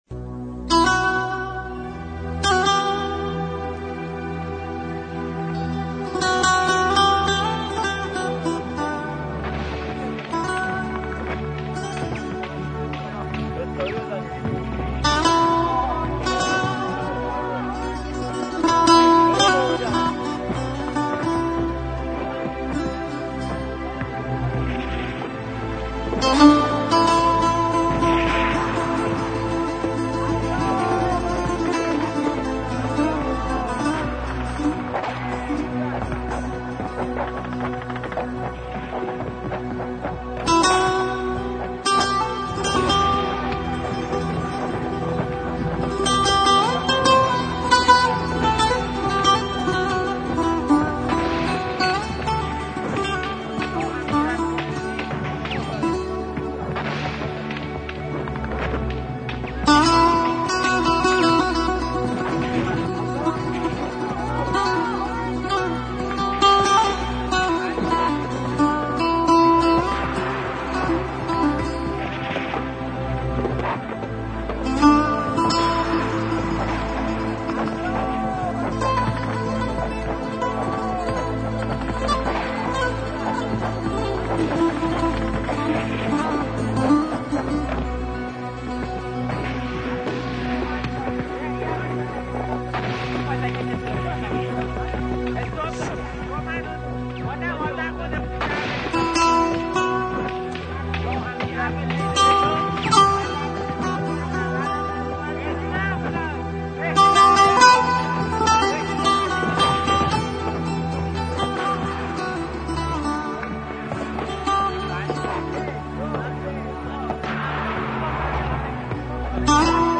***منتخب زیباترین صداهای دفاع مقدس***
صدای جبهه1